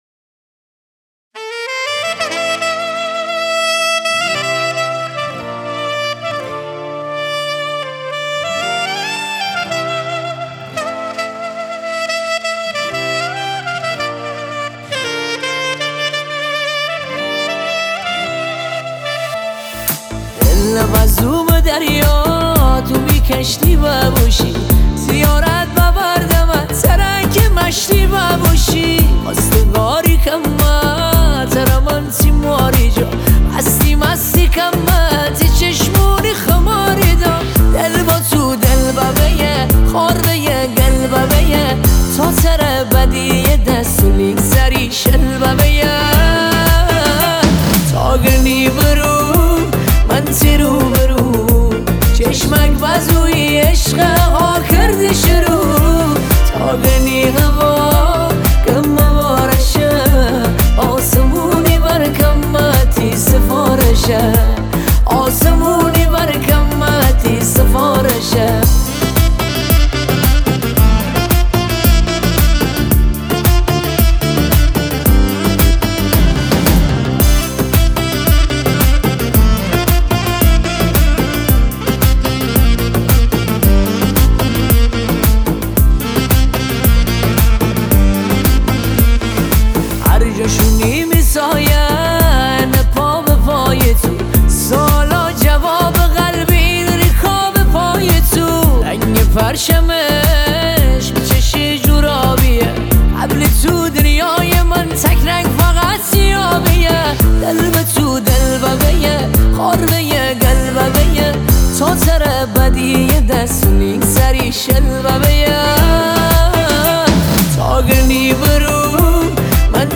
ورژن مازندرانی